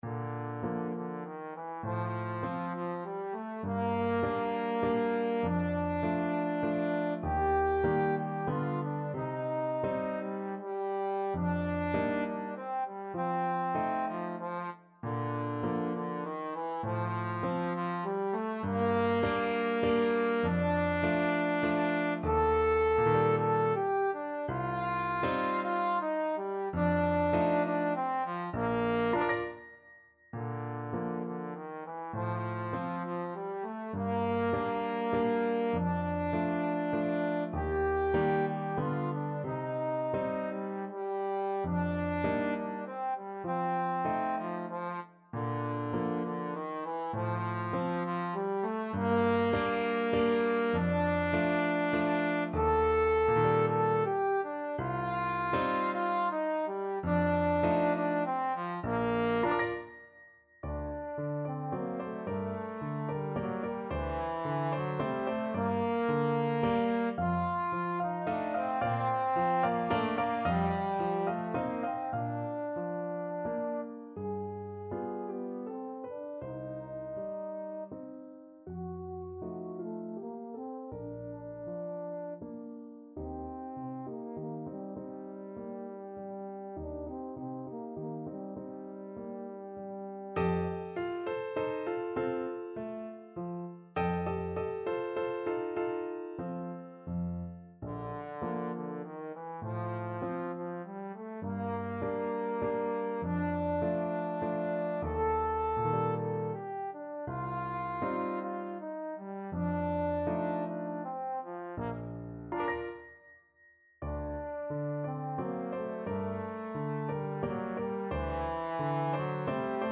Trombone
Bb major (Sounding Pitch) (View more Bb major Music for Trombone )
~ = 100 Tranquillamente
3/4 (View more 3/4 Music)
D4-A5
Classical (View more Classical Trombone Music)
merikanto_valse_lente_op33_TBNE.mp3